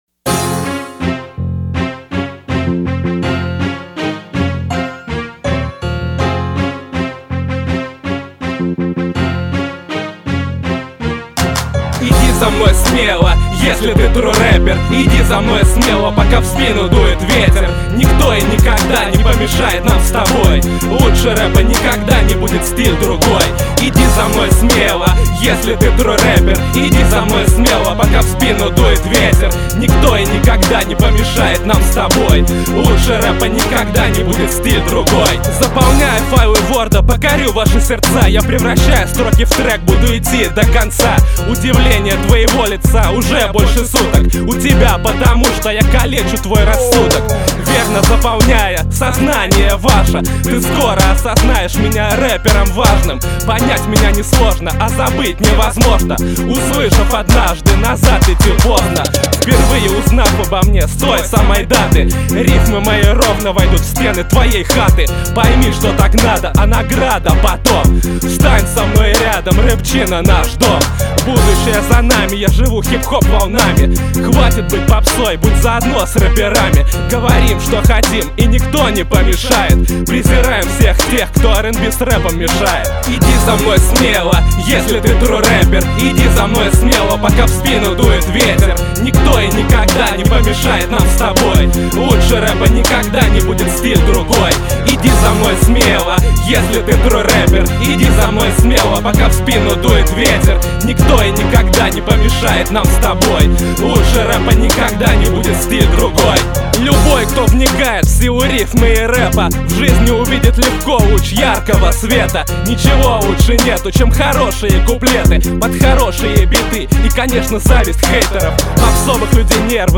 (имеется в мп3 треке этот рэпчик, качайте на здоровье)